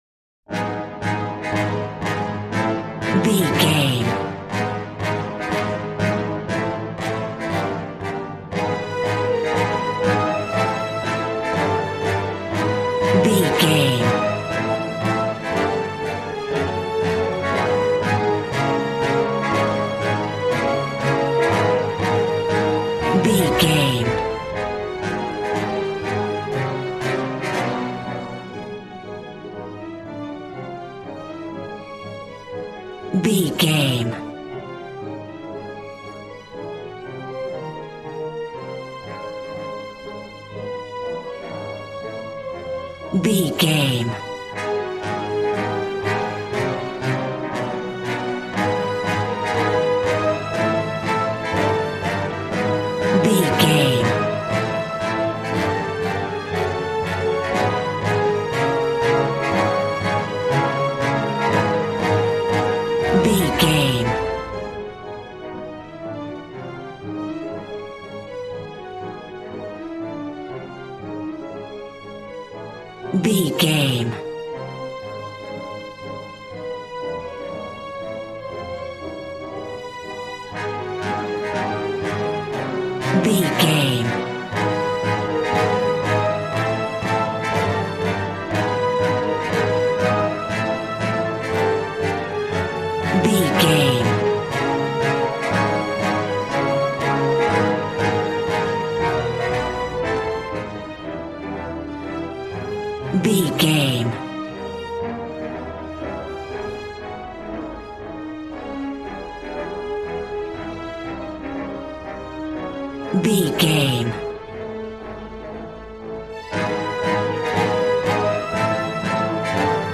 Ionian/Major
dramatic
powerful
epic
percussion
violin
cello